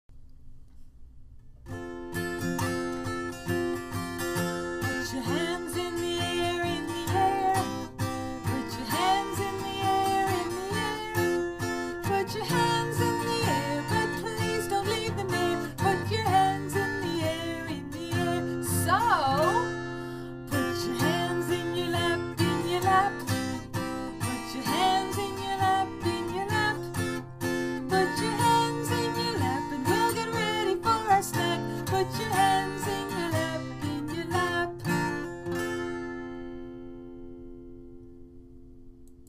Tune: "If You're Happy and You Know it"